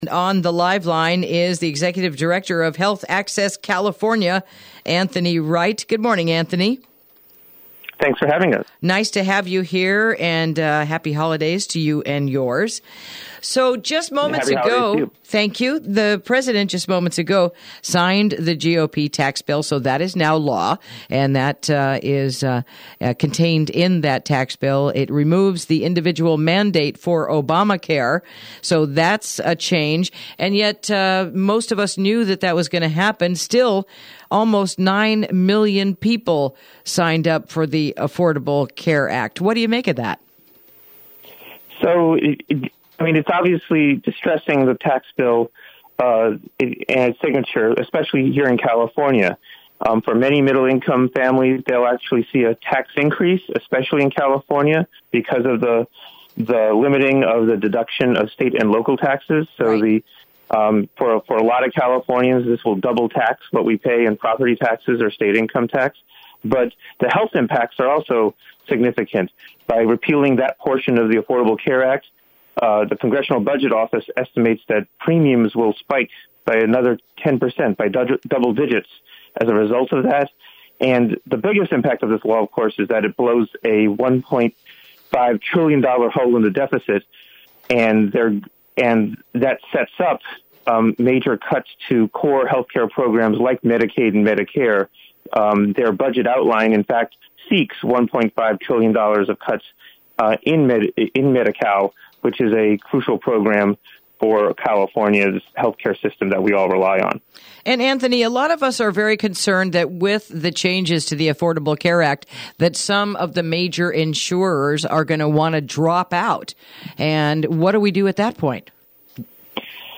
Interview: What Will Health Insurance in California Look Like After the GOP Tax Bill | KSRO 103.5FM 96.9FM & 1350AM